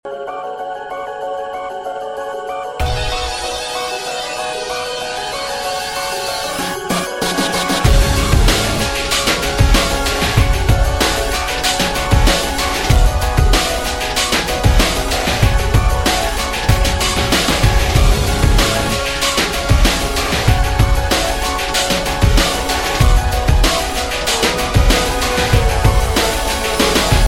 брутальные
без слов
Alternative Rock
Electronic Rock
саундтрек